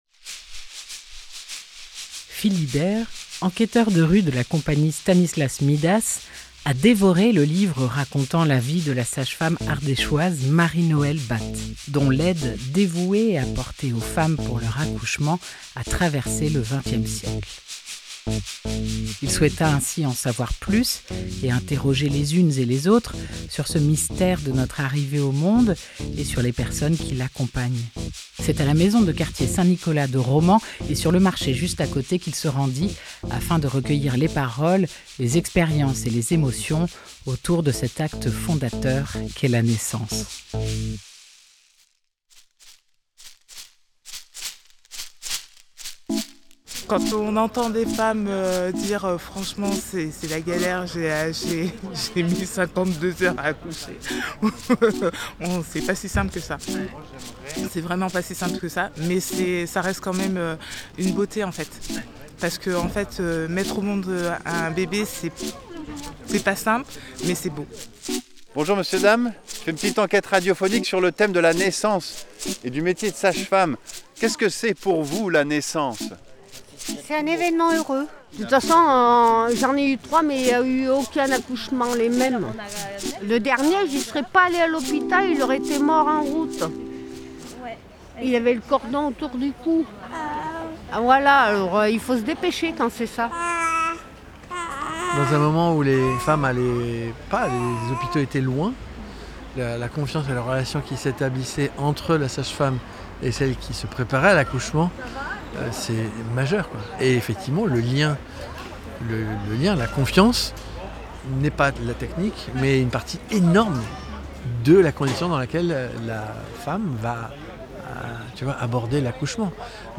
15 août 2024 8:20 | Emissions Spéciales, reportage
C’est à la maison de Quartier Saint Nicolas de Romans et sur le marché juste à côté qu’il se rendit afin de recueillir les paroles, les expériences et les émotions autour de cet acte fondateur qu’est la naissance.